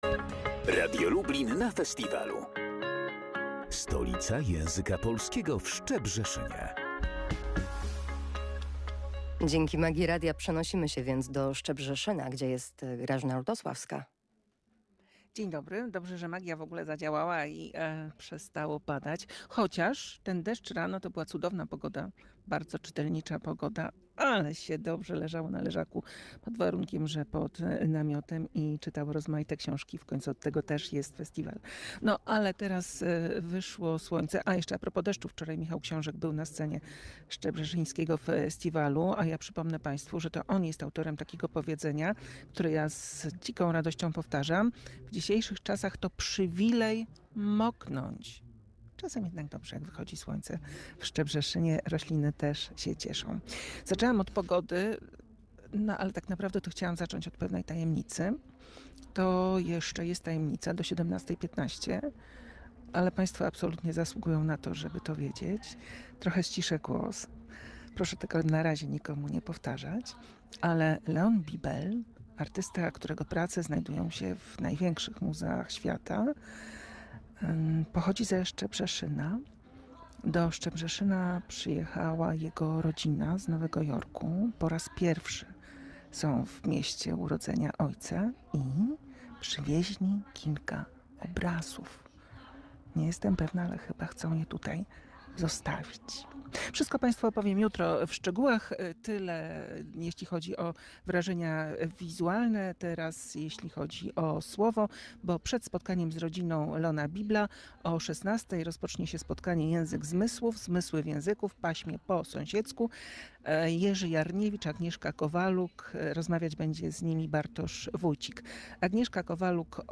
W Szczebrzeszynie trwa Festiwal Stolica Języka Polskiego. Jedenasta edycja wydarzenie przyciąga tłumy miłośników literatury oraz językoznawców.